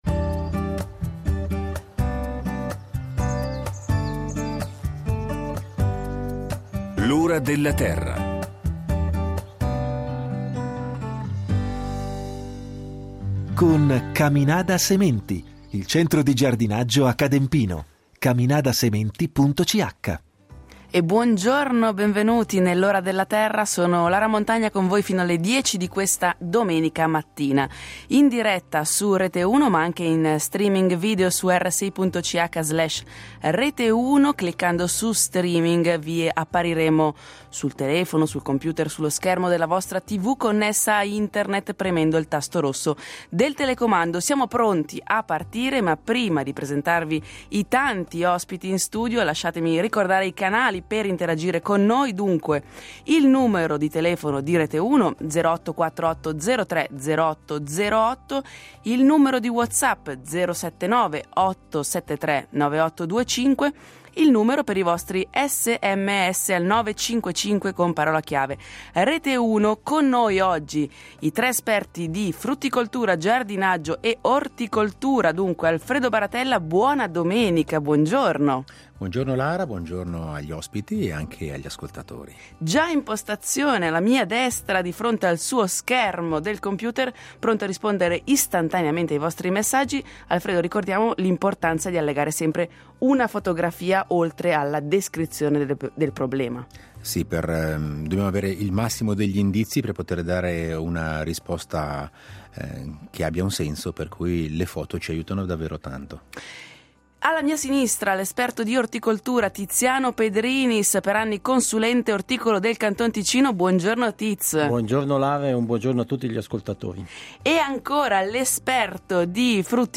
In studio anche gli esperti del programma, con i loro consigli di giardinaggio, orticoltura e frutticoltura.